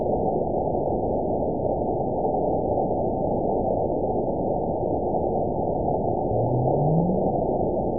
event 920073 date 02/21/24 time 21:03:16 GMT (1 year, 9 months ago) score 9.60 location TSS-AB05 detected by nrw target species NRW annotations +NRW Spectrogram: Frequency (kHz) vs. Time (s) audio not available .wav